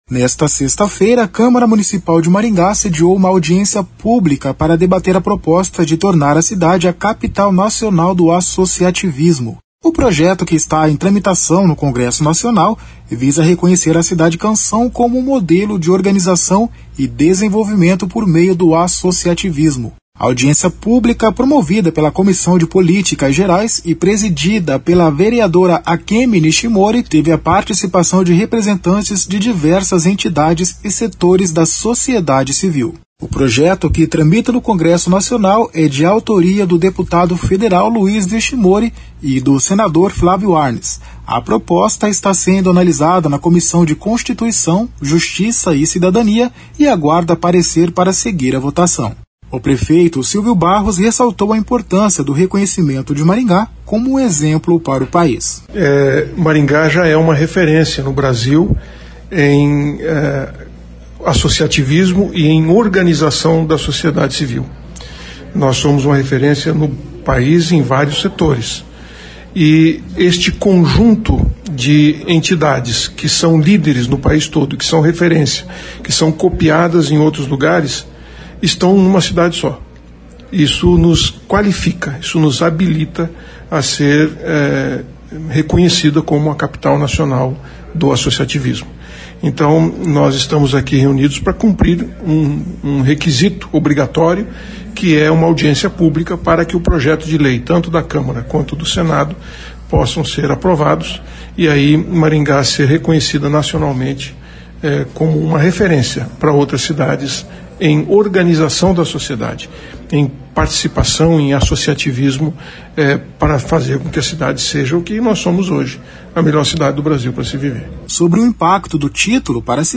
Nesta sexta-feira (7), a Câmara Municipal de Maringá sediou uma audiência pública para debater a proposta de tornar a cidade a Capital Nacional do Associativismo.
O prefeito Silvio Barros ressaltou a importância do reconhecimento de Maringá como um exemplo para o país.